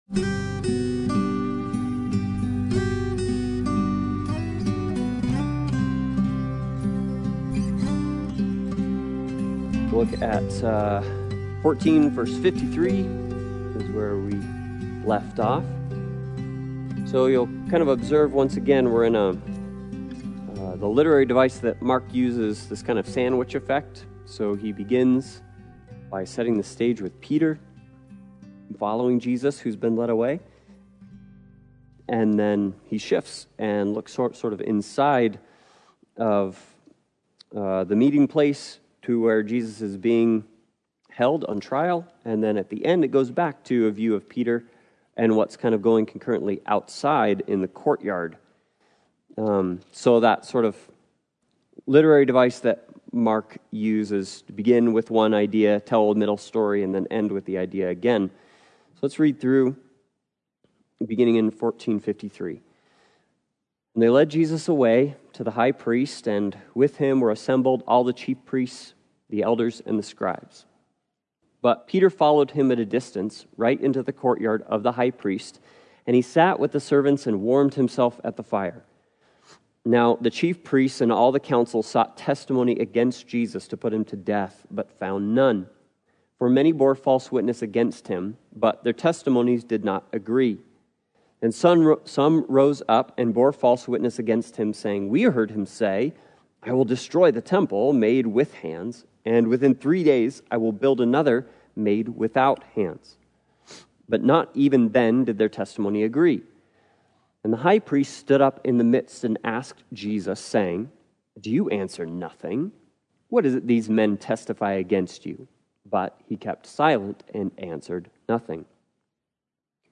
Passage: Mark 14:52 Service Type: Sunday Bible Study « The Virtuous Woman